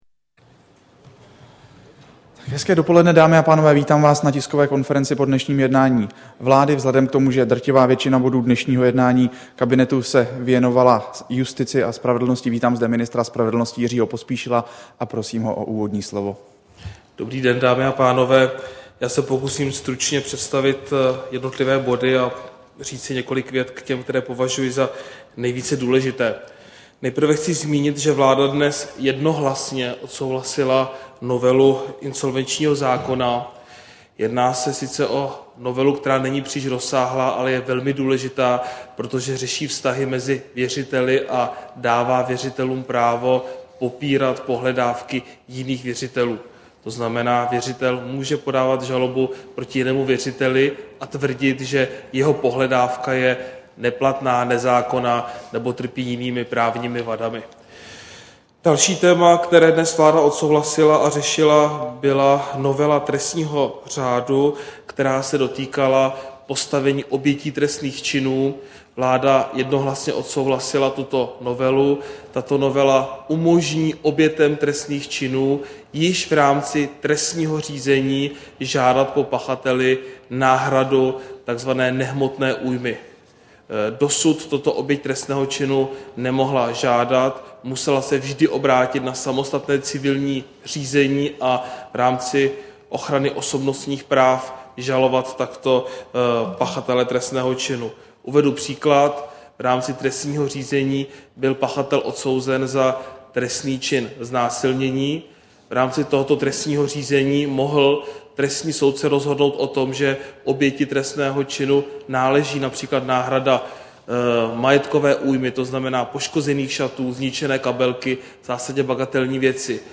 Tisková konference po jednání vlády, 12. ledna 2011